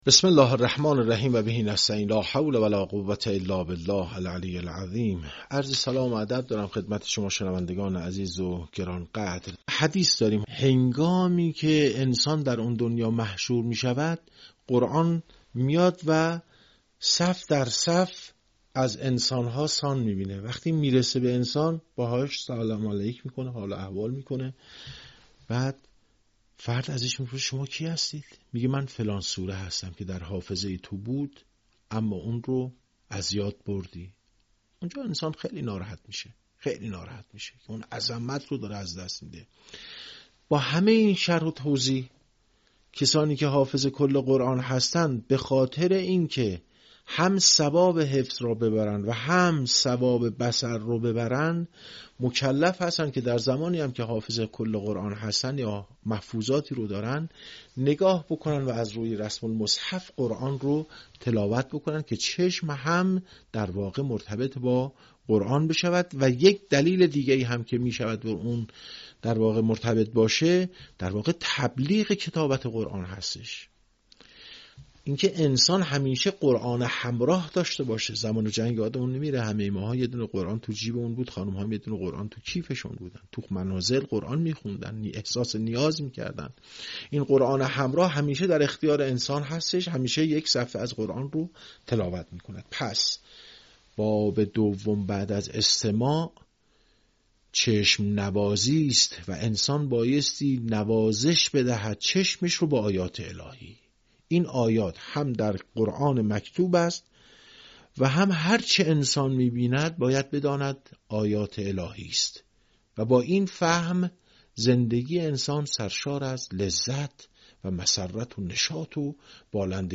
یکی از مهم‌ترین سیاست‌های ایکنا نشر مبانی آموزشی و ارتقای سطح دانش قرائت قرآن مخاطبان گرامی است. به همین منظور مجموعه آموزشی شنیداری (صوتی) قرآنی را گردآوری و برای علاقه‌مندان بازنشر می‌کند.